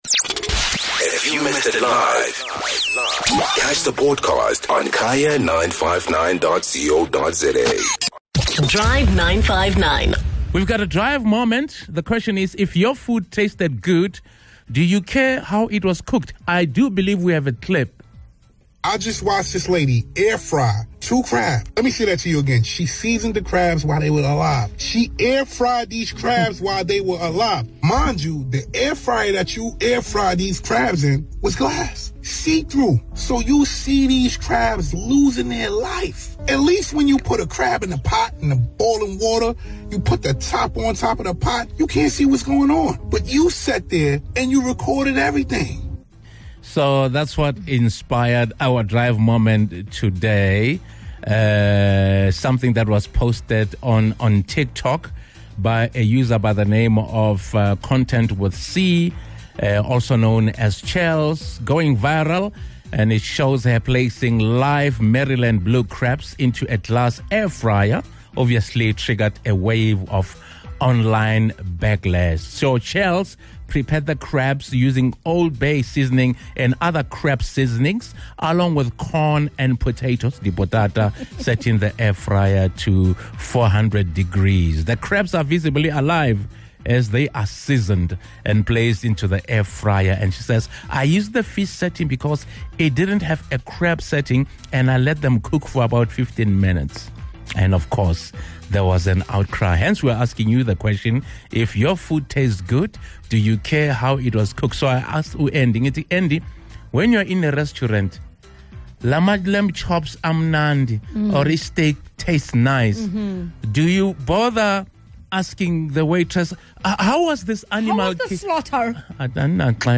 Speaking broadly, about all dishes and all types of food, do you care about how your food is prepared? From the ethics of slaughtering, to the cleanliness of the environment where the food is cooked, a lot goes into food preparation that we cant see, beyond the final plate. take a listen to the debates that happened in-studio with the Drive 959 Family!